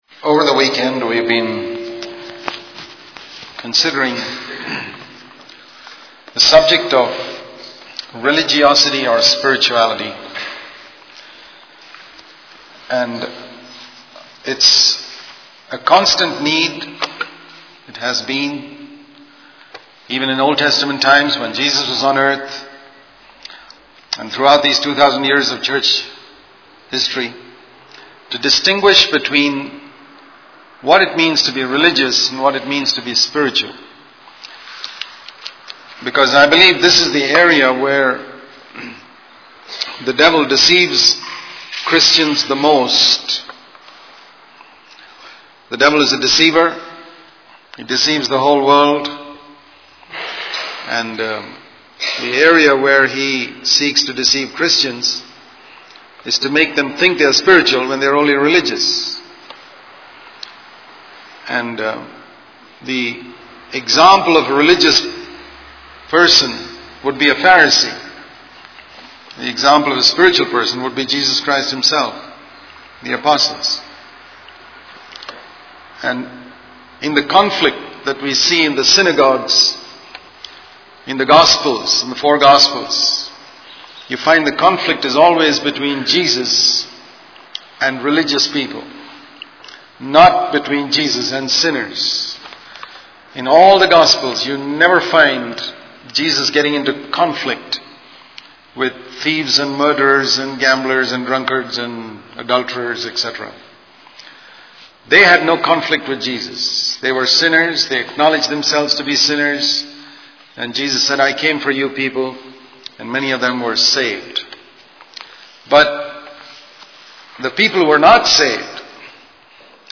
In this sermon, the speaker discusses the blessings and consequences of obedience to God's commandments.